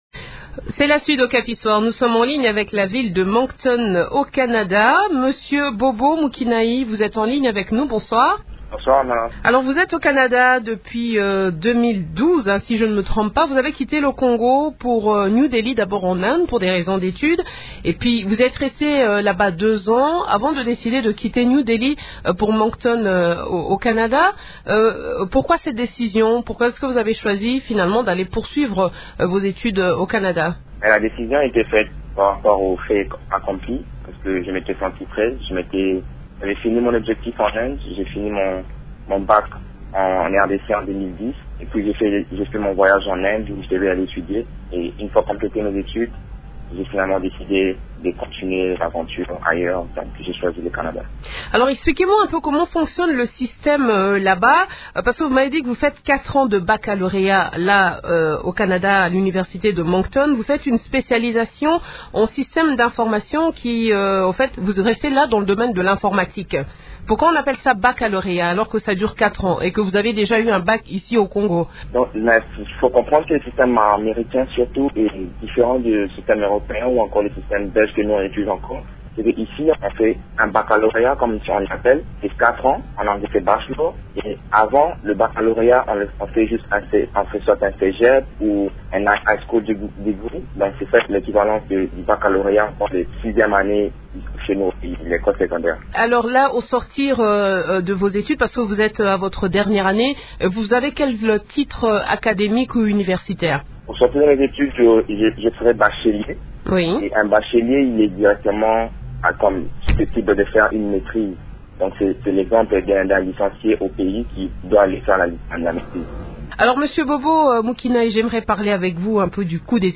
Congolais vivant au Canada.